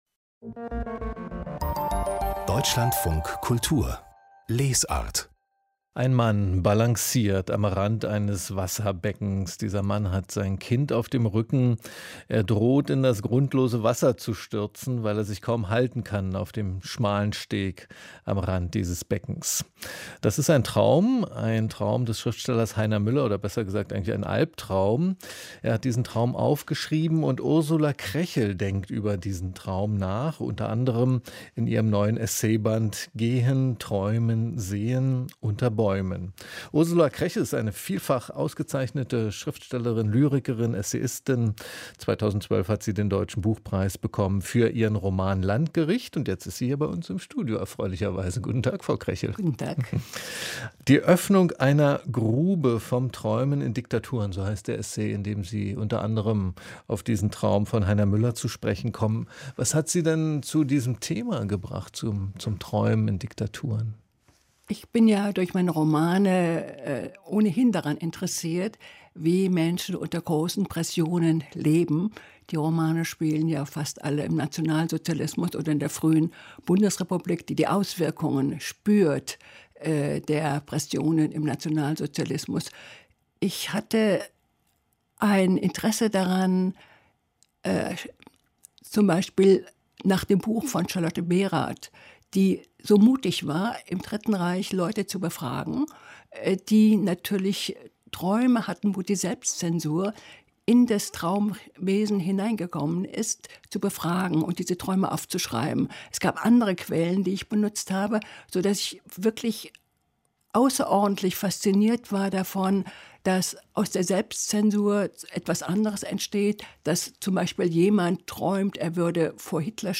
Ursula Krechel im Gespräch mit